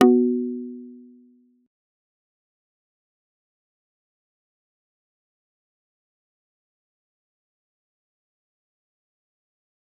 G_Kalimba-B3-pp.wav